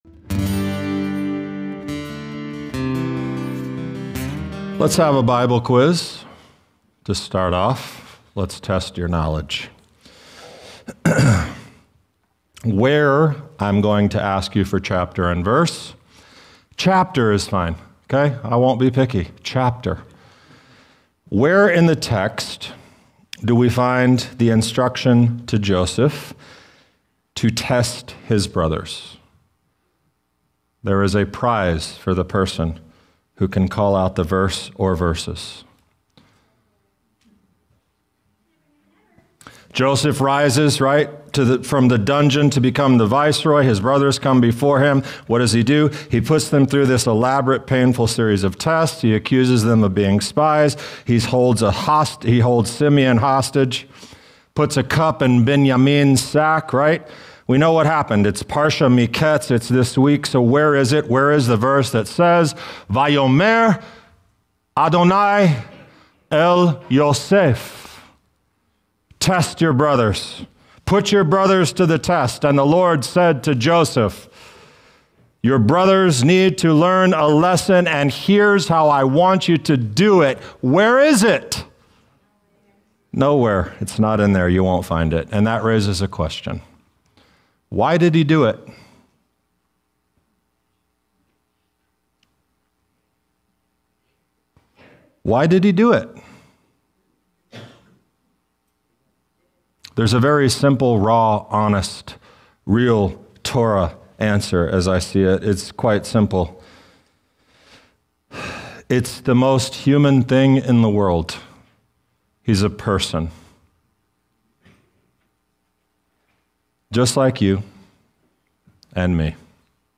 This teaching wrestles honestly with one of the most emotionally charged moments in the Torah.